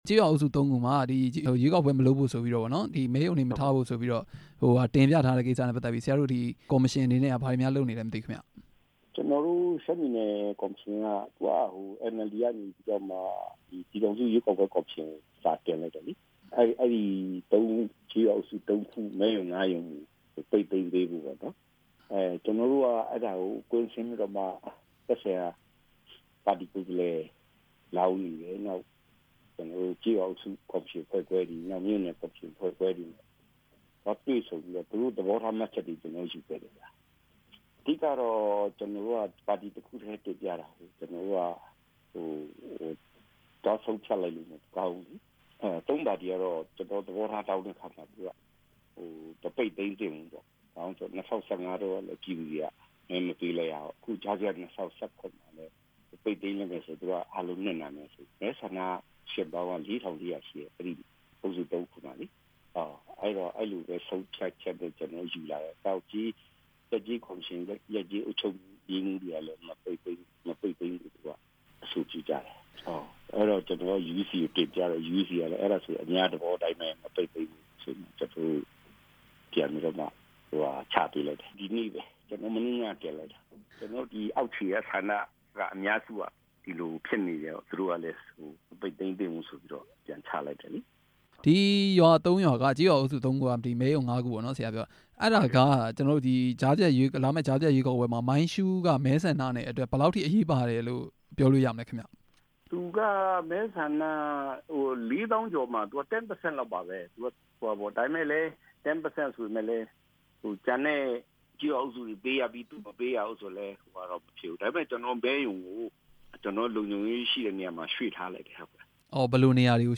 အဲဒီကျေးရွာအုပ်စု ၃ခုမှာ မဲရုံတွေကို မဖျက်သိမ်းဘဲ နေရာပြောင်းရွှေ့ကျင်းပဖို့ စီစဉ်ထားတယ်လို့ ရှမ်းပြည်နယ် ရွေးကောက်ပွဲကော်မရှင် ဥက္ကဌ ဦးစိုင်းထွန်းမြ က ပြောပါတယ်။